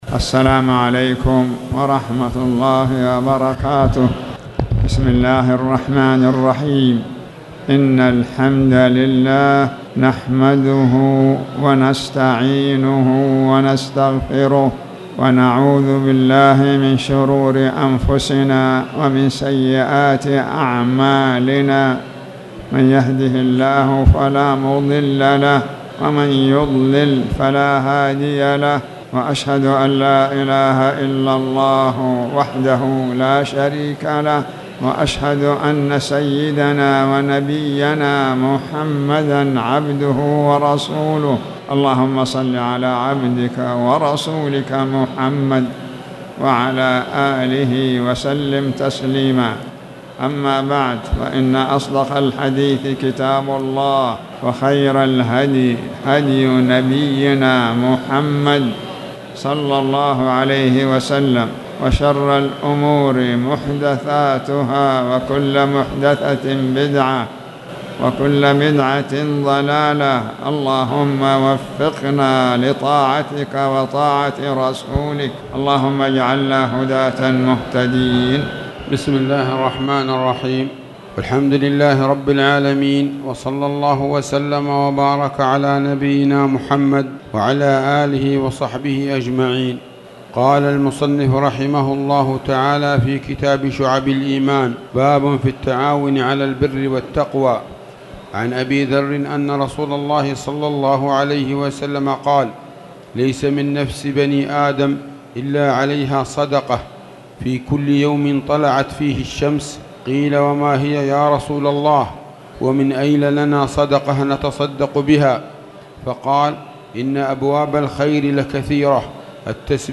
تاريخ النشر ٢٥ شعبان ١٤٣٨ هـ المكان: المسجد الحرام الشيخ